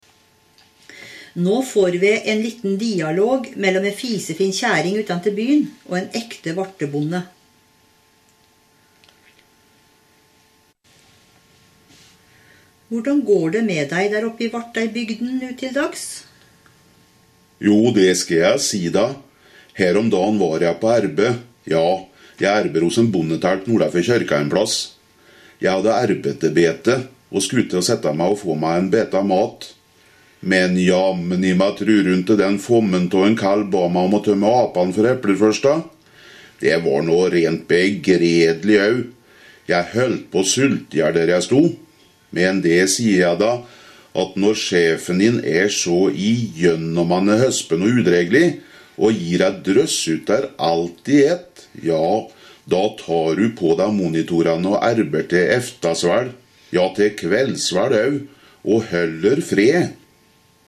Varteig-dialekt i både skrift og tale:
Både hefte og CD innledes for øvrig med en tenkt dialog, mellom en rimelig fornem og fin byfrue og en ekte bondetalp fra Varteig.
Utdraget av en samtale mellom byfruen og bonden fra Varte:
samtale.mp3